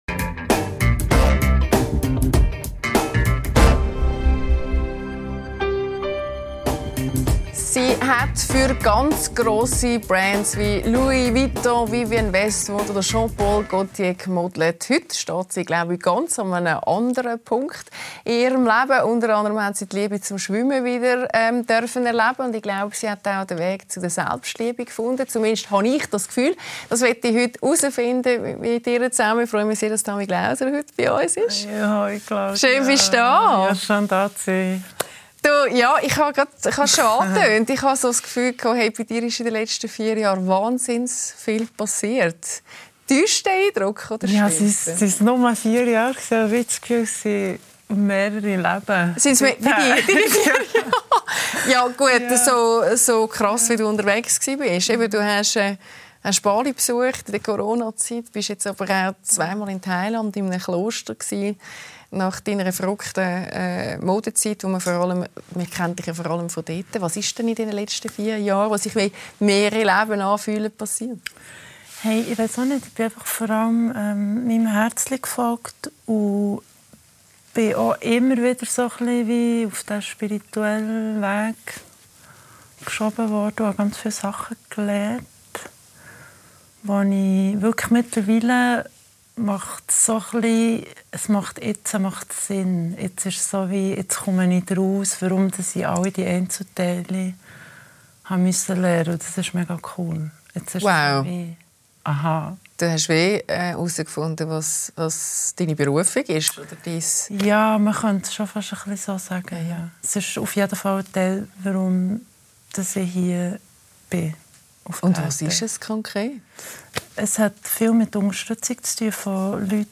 Mit Tamy Glauser ~ LÄSSER ⎥ Die Talkshow Podcast